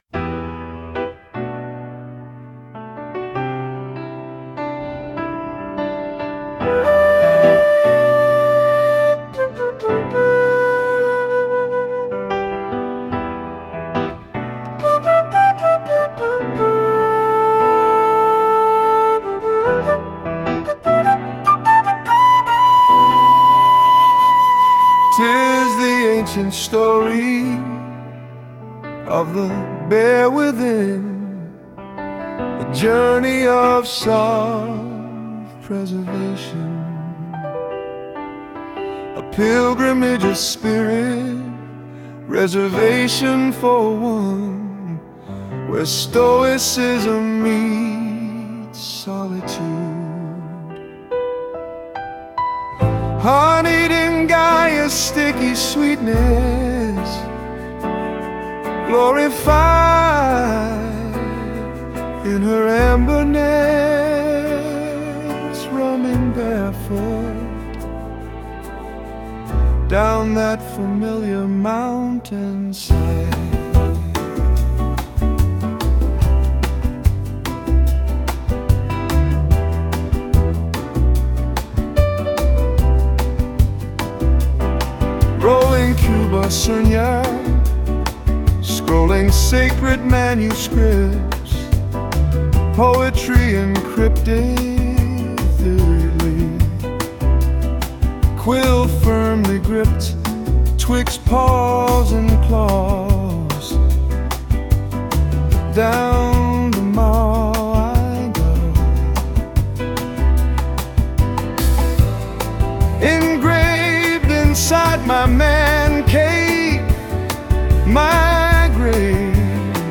A beautiful blend of wit and wisdom, wrapped in a lilting montage of smooth vocals and kick-ass music for the heart, mind and soul.